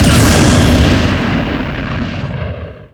SSB Stage Fall Sound Effect